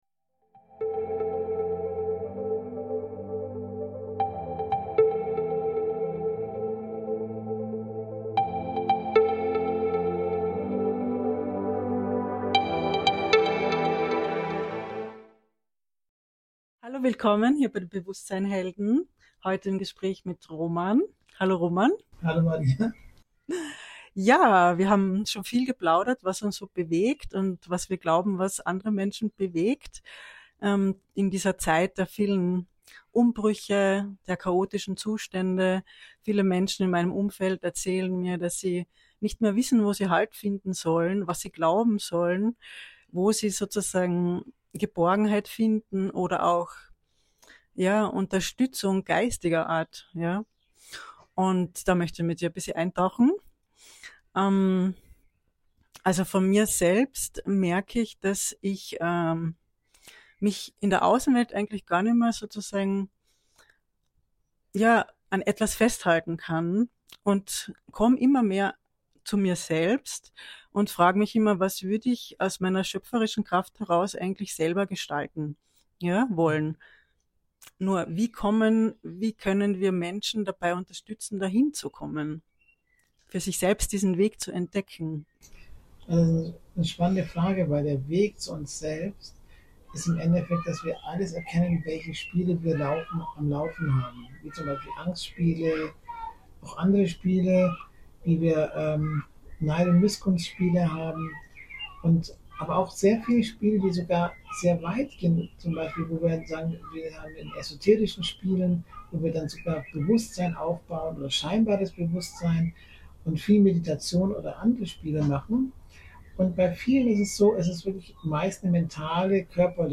Bewusst-sein-Helden on Tour - Teil 3 der Interviewreihe